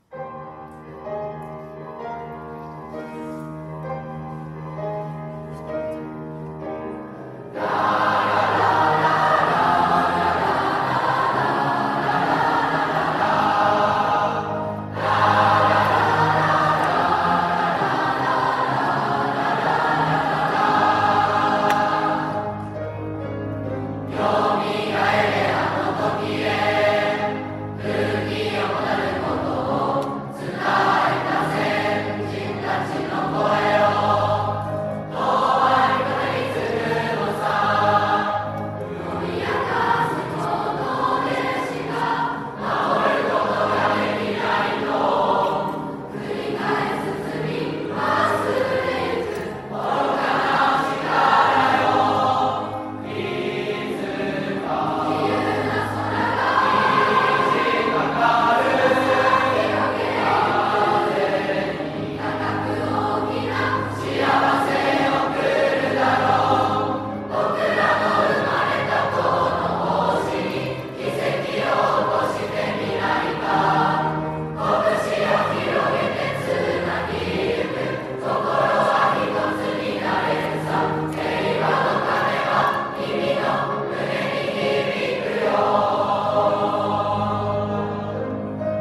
3月18日 48期生 ２年生合唱コンクール
また学年合唱は、全員の姿から、この1年で取り組んできた集大成だという気持ちが伝わる、すごいエネルギーの合唱でした。
学年合唱 「HEIWAの鐘」 ２年生学年合唱 ⇐合唱の一部が聞けます。